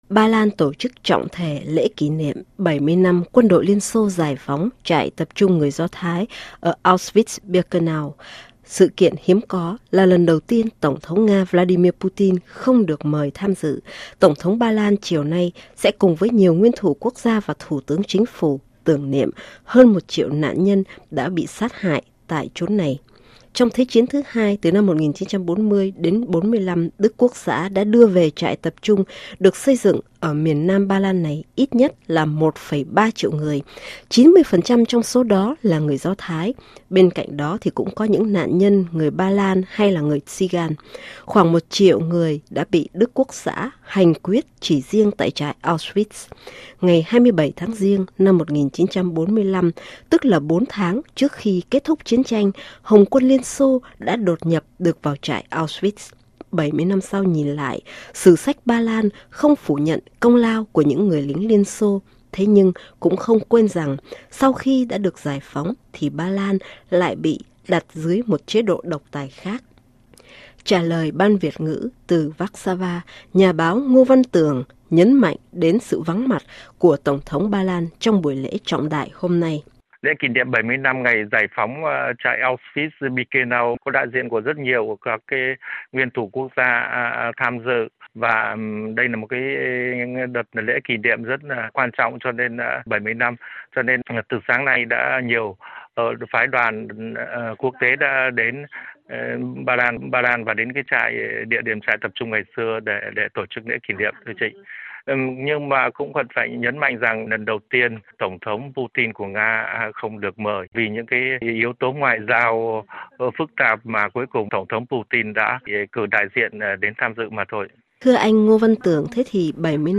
Trả lời ban Việt Ngữ, từ Vacxava